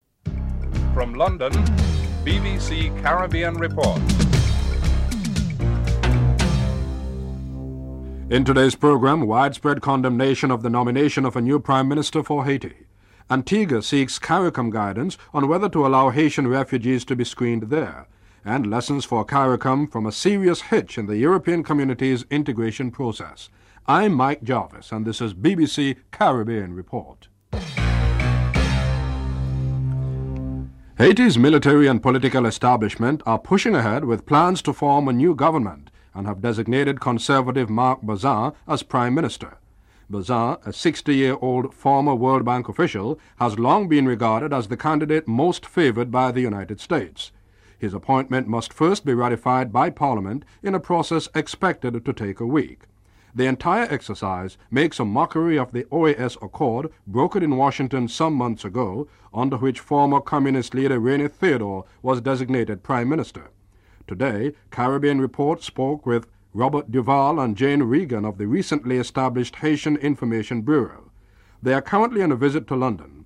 1. Headlines (00:00-00:30)